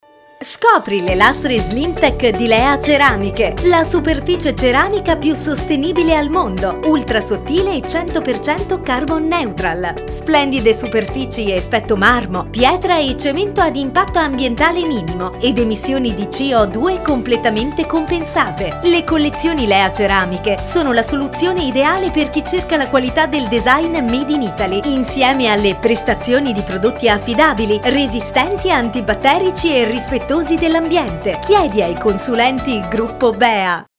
LeaCeramicheSpot.mp3